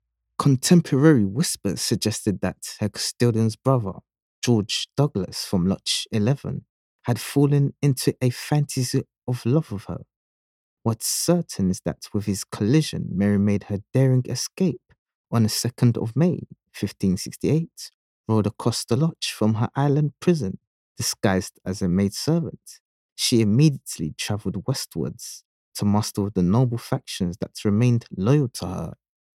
Professional voice actor - clear, soft, approachable, melodic, versatile, positive for Commercial, Animation/Video Game, Audiobook, E-learning, Documentary/Corporate Narration, and Broadcast...